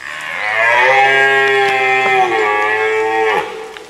W tym czasie, od zmierzchu do świtu, samce jeleni, czyli byki, chcąc zwabić łanie i odstraszyć rywali, wydają głośne odgłosy.